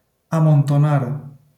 wymowa:
IPA[ã.mõn̦.to.ˈnaɾ]